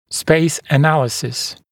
[speɪs ə’næləsɪs][спэйс э’нэлэсис]пространственный анализ